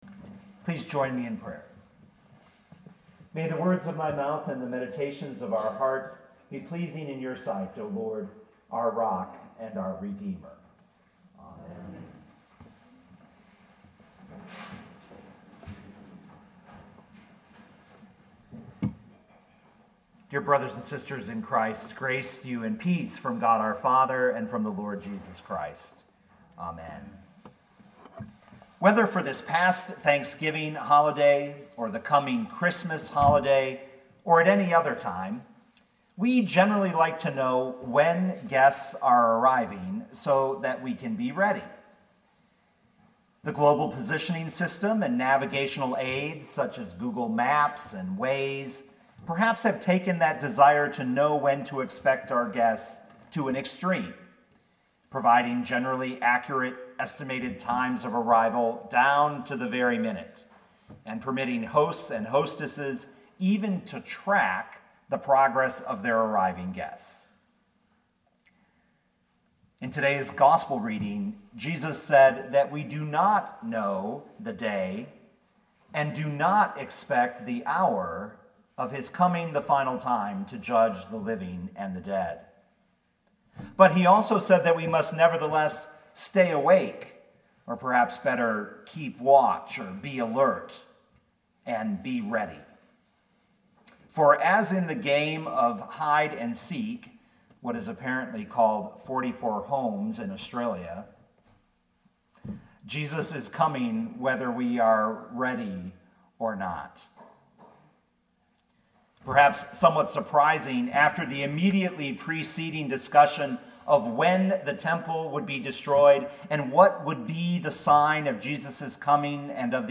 2019 Matthew 24:36-44 Listen to the sermon with the player below, or, download the audio.